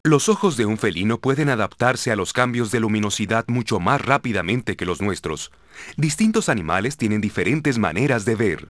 LION03NJ.WAV